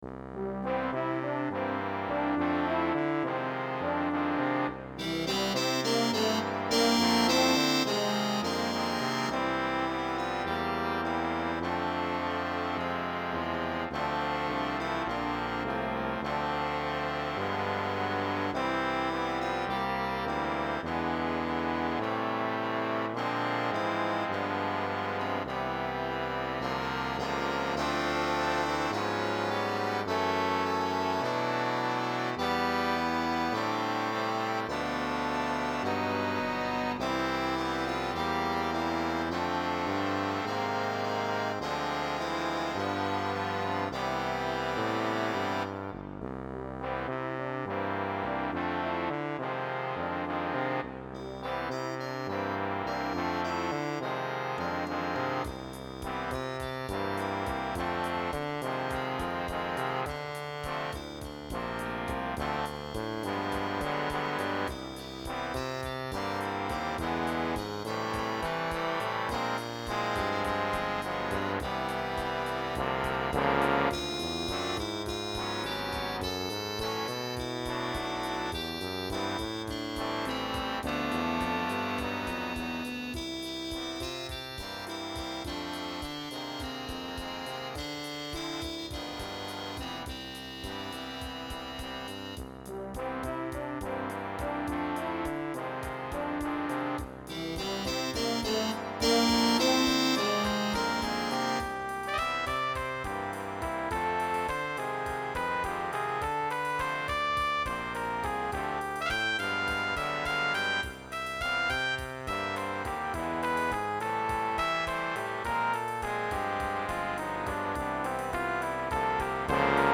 in a gentle, laidback ‘Bossa Nova’ style.